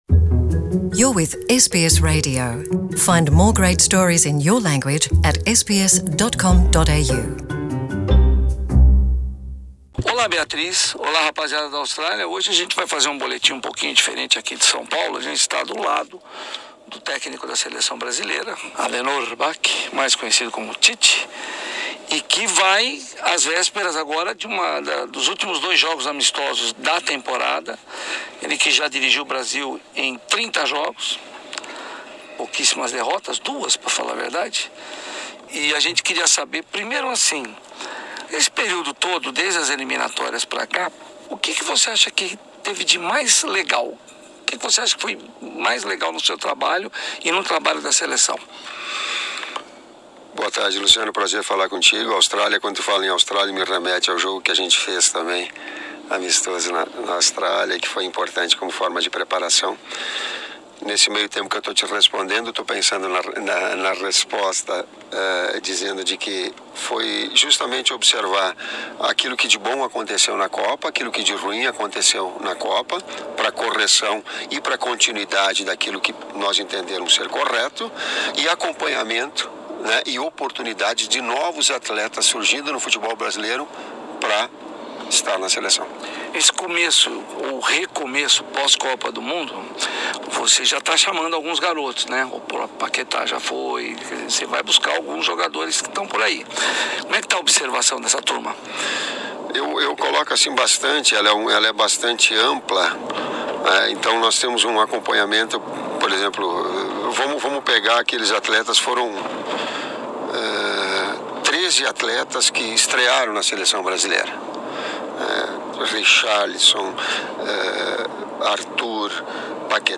Tite diz ter ficado "em paz comigo mesmo" após a Copa da Rússia: ouça a entrevista exclusiva à SBS em Português